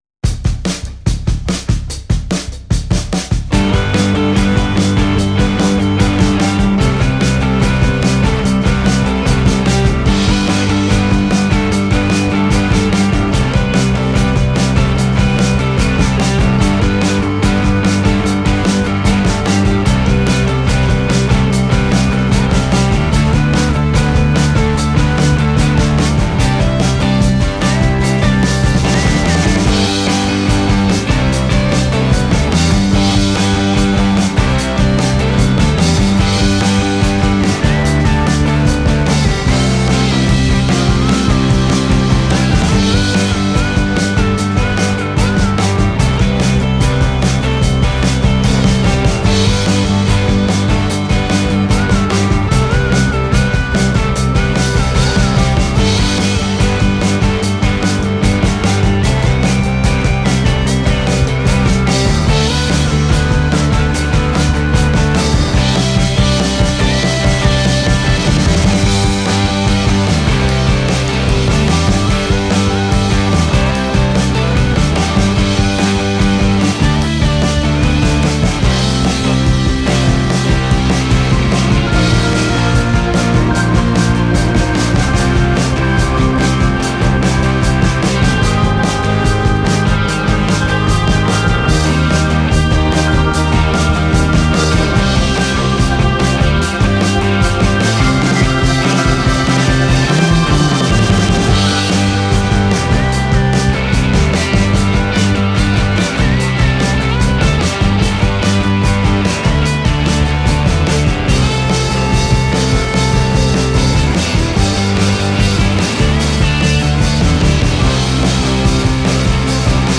描述：摇滚乐|明快
标签： 合成器 贝司 电吉他
声道立体声